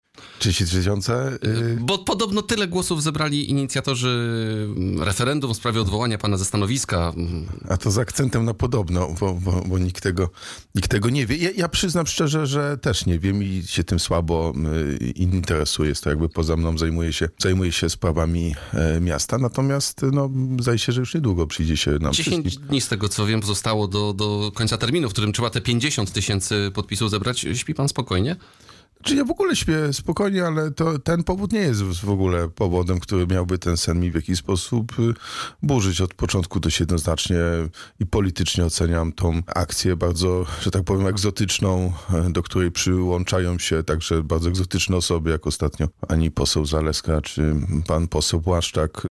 – Staram się szukać przebaczenia i wybaczać nawet tym, którzy czynią mi źle – powiedział w rozmowie z Radiem Rodzina prezydent Wrocławia, Jacek Sutryk.